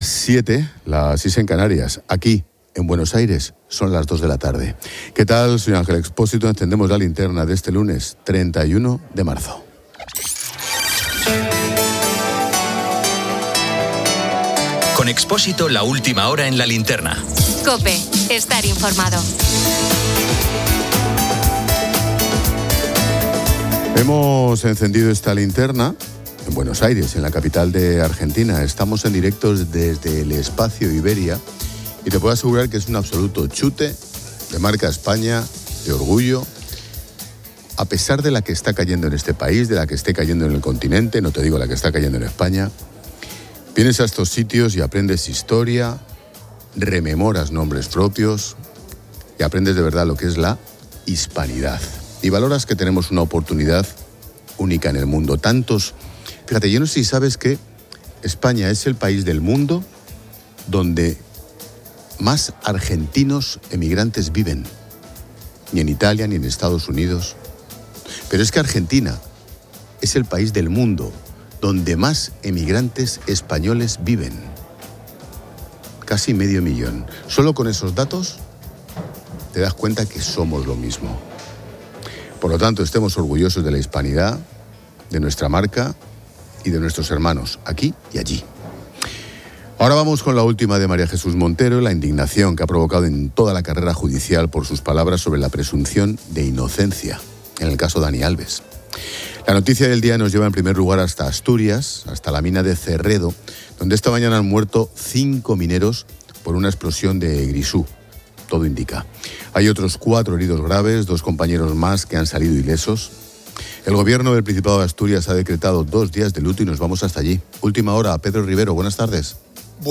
Hemos encendido esta linterna en Buenos Aires, en la capital de Argentina. Estamos en directo desde el Espacio Iberia y te puedo asegurar que es un absoluto chute de marca España, de orgullo, a pesar de la que está cayendo en este país, de la que esté cayendo en el continente, no te digo la que está cayendo en España.